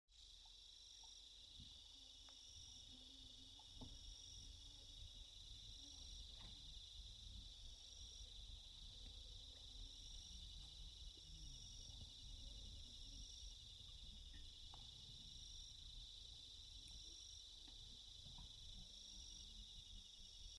Здесь вы найдете успокаивающие композиции из шума прибоя, пения цикад и легкого ветра — идеальный фон для отдыха, работы или сна.
Шепот летнего заката в природе